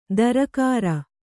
♪ darakāra